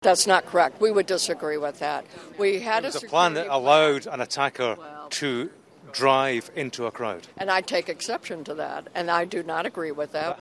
The city’s chief of police, Anne Kirkpatrick’s denied there were failings.